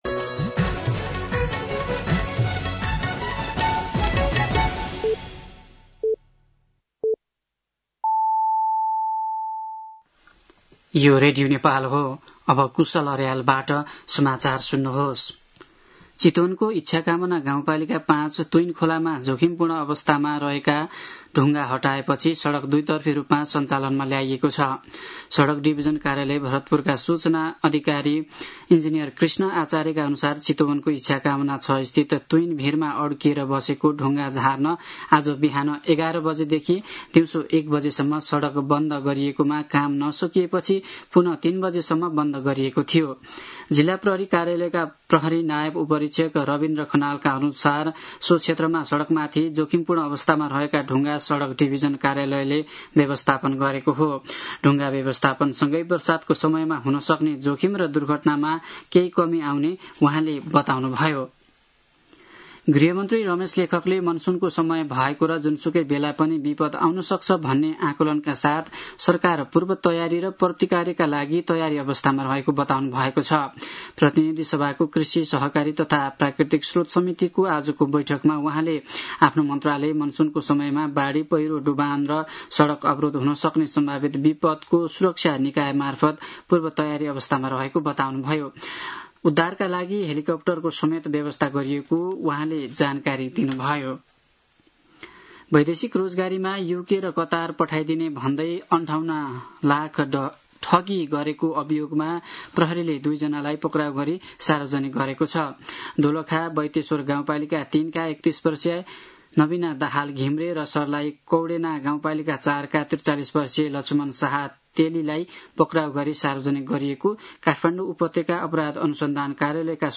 साँझ ५ बजेको नेपाली समाचार : १२ असार , २०८२
5-pm-news-3-12.mp3